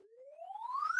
dm_sent.ogg